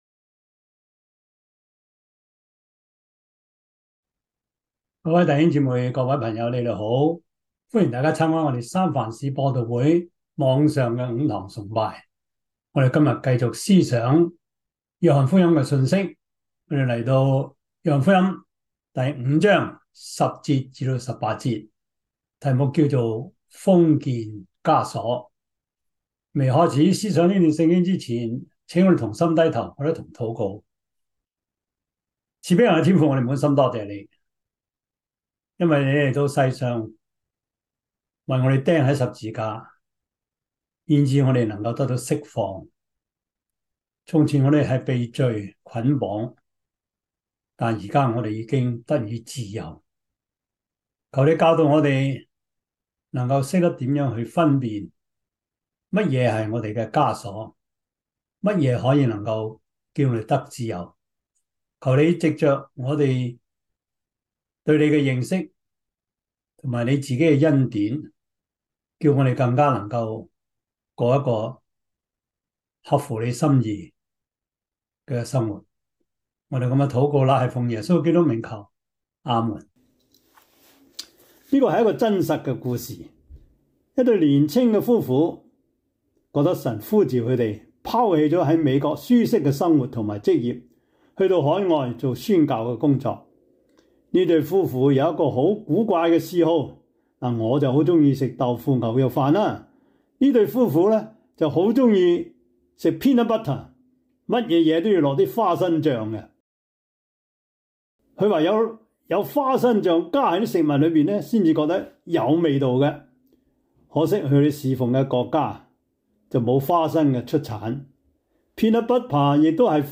約翰福音 5:10-18 Service Type: 主日崇拜 約翰福音 5:10-18 Chinese Union Version